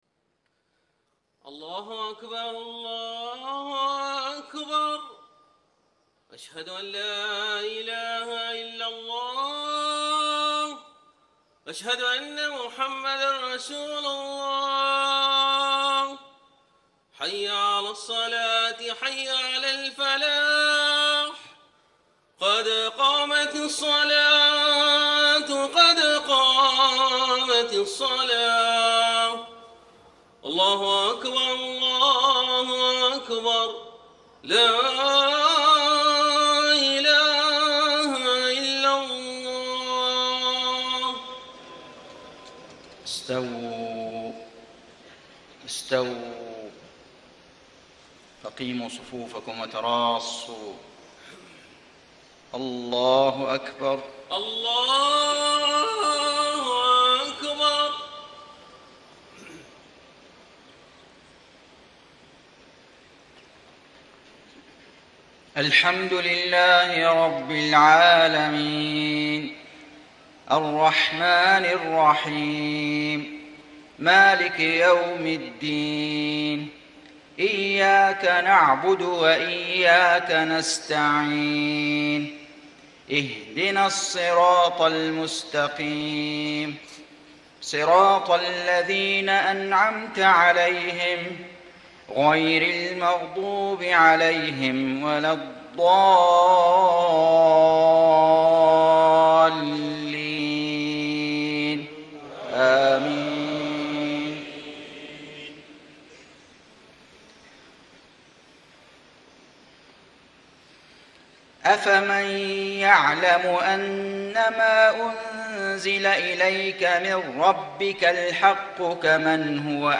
مغرب 6 شعبان ١٤٣٥ من سورة الرعد > 1435 🕋 > الفروض - تلاوات الحرمين